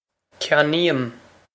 ceannaím kyan-eem
This is an approximate phonetic pronunciation of the phrase.